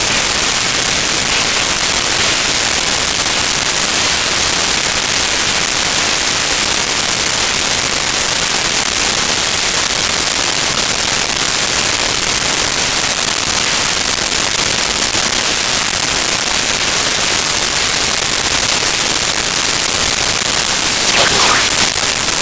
Radio Static New
Radio Static New.wav